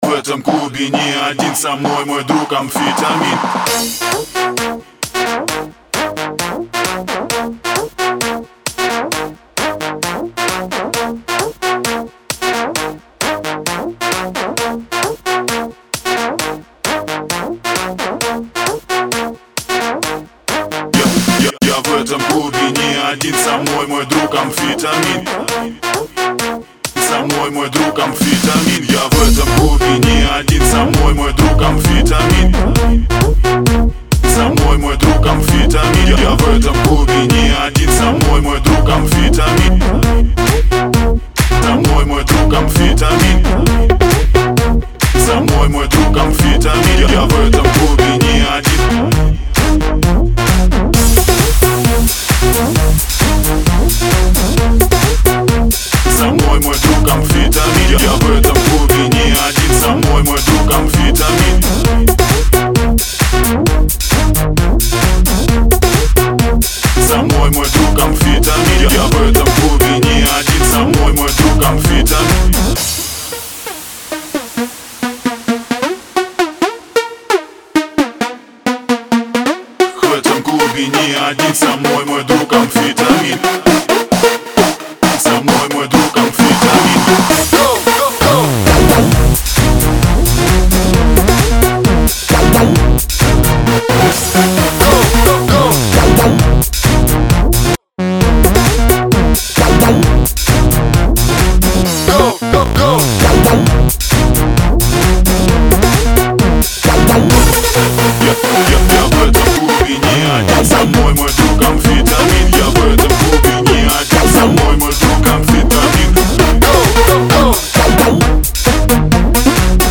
Жанр:House